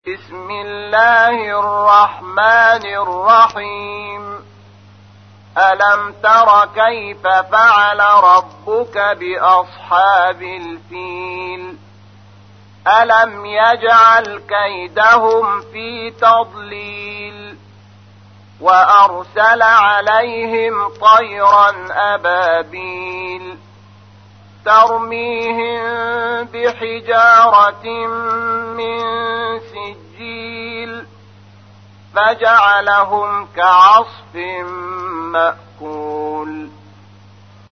تحميل : 105. سورة الفيل / القارئ شحات محمد انور / القرآن الكريم / موقع يا حسين